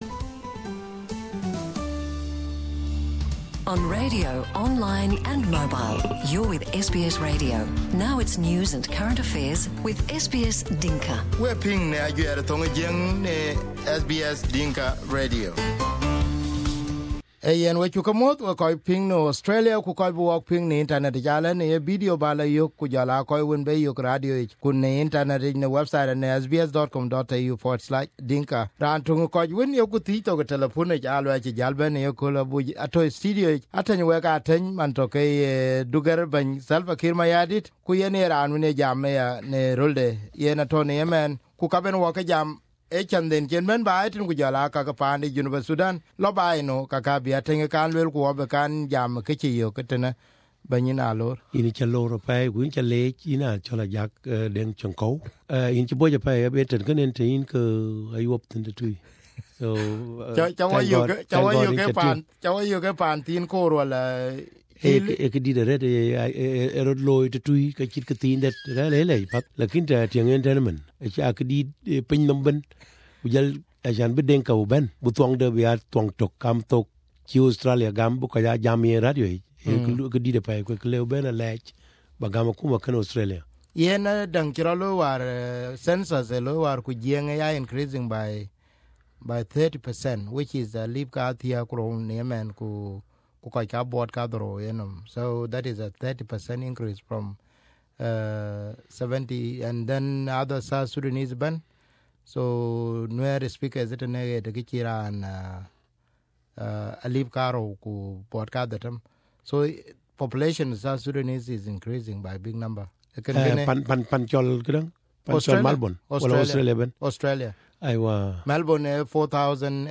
South Sudan Presidential Press Secretary Ateny Wek Ateny who is on his private visit to Australia came to SBS studio today on 13/07/2017.
Here is part one of his interview.